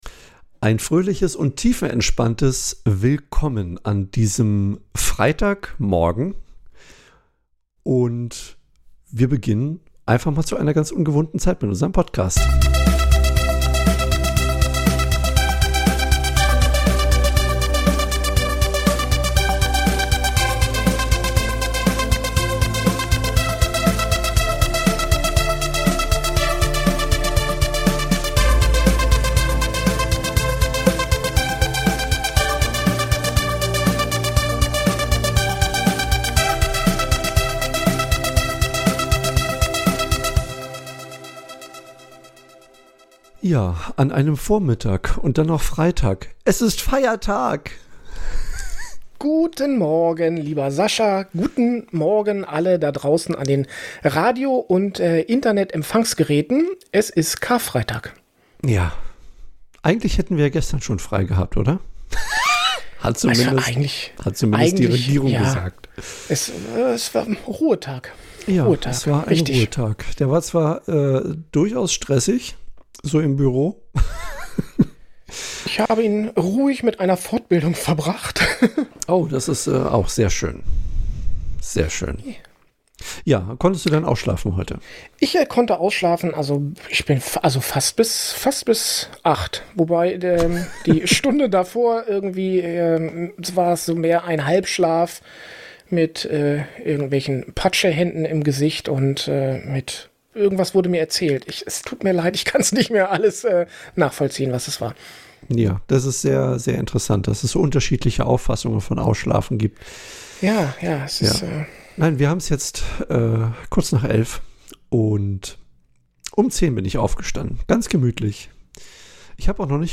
Außerdem wird es hier und da ein wenig musikalisch, denn diese Woche war World Piano Day.
Insofern freut Euch auf eine tiefenentspannte Episode zum verlängerten Wochenende.